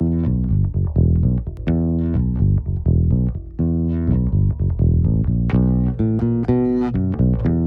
32 Bass PT1.wav